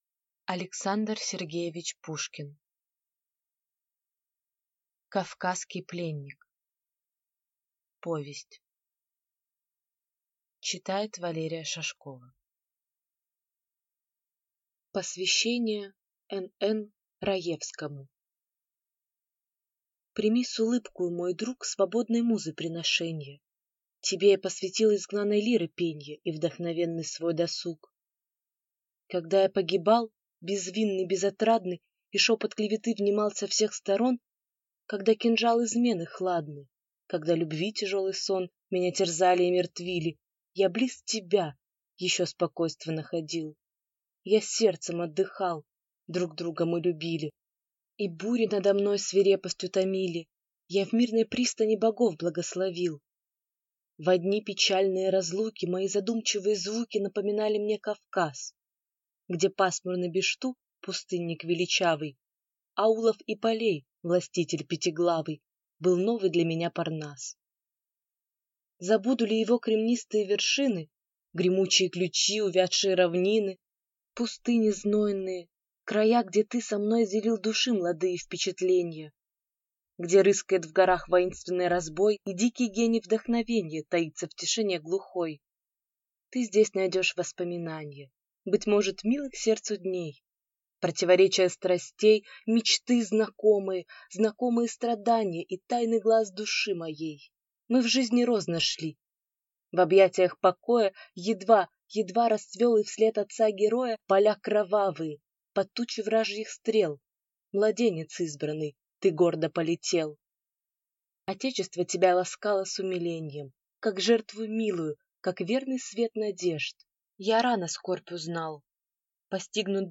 Аудиокнига Кавказский пленник | Библиотека аудиокниг